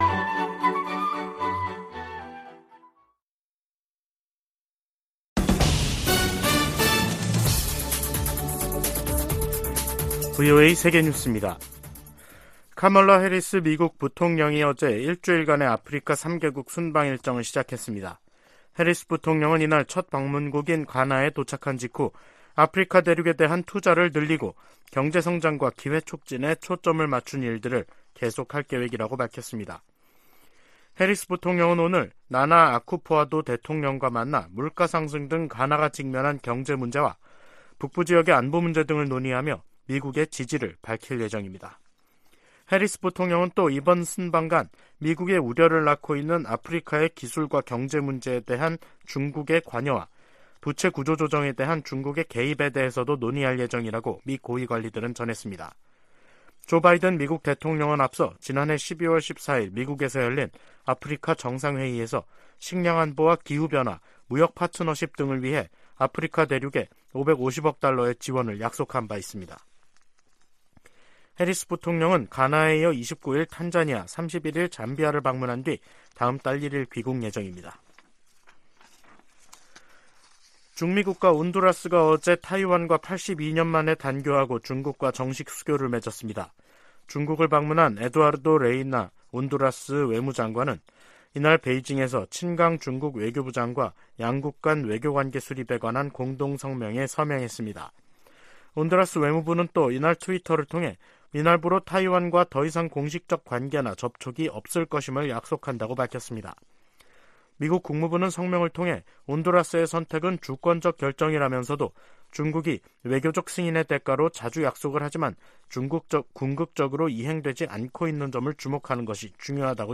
VOA 한국어 간판 뉴스 프로그램 '뉴스 투데이', 2023년 3월 27일 2부 방송입니다. 북한이 또 다시 단거리 탄도미사일(SRBM) 두 발을 동해상으로 발사했습니다. 미 국방부는 북한의 수중 핵폭발 시험에 대해 우려를 나타내고, 한국과 다양한 훈련을 계속 수행할 것이라고 밝혔습니다. 미 공화당 중진 상원의원이 한국에 핵무기를 재배치하는 방안을 고려해야 한다고 주장했습니다.